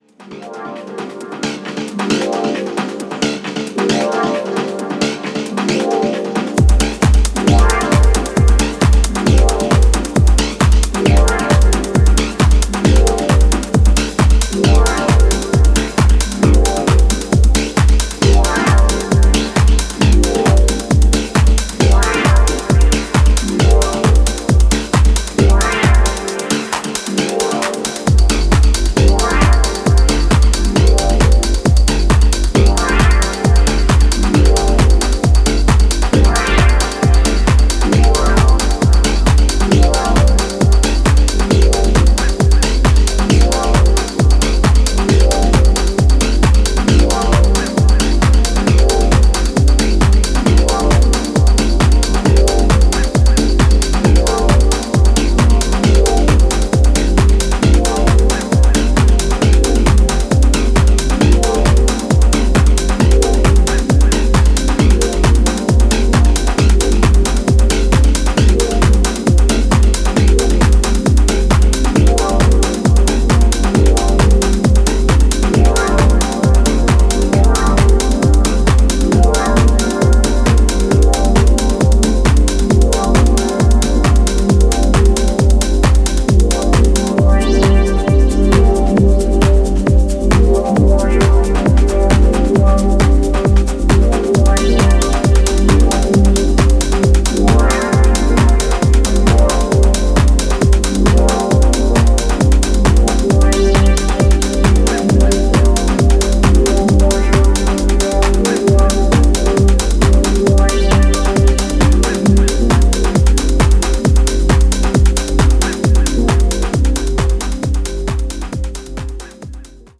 a four-tracker made for working DJs